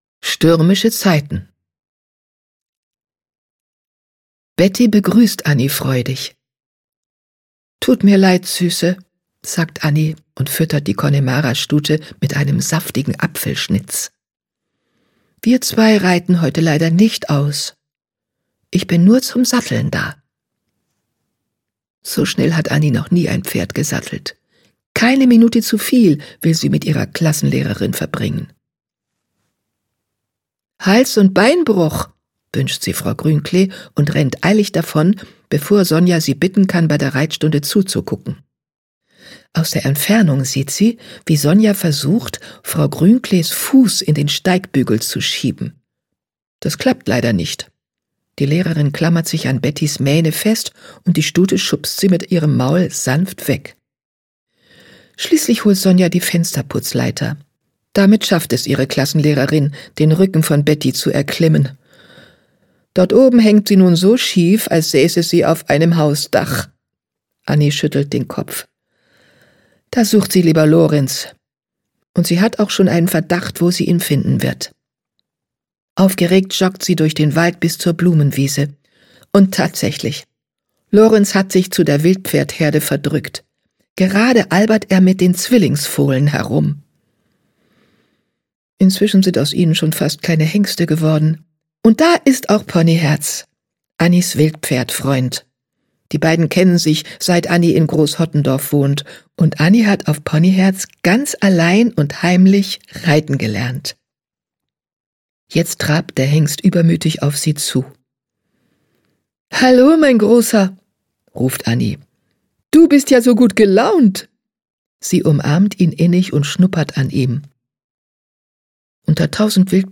Ponyherz 14: Ponyherz im Sturm - Usch Luhn - Hörbuch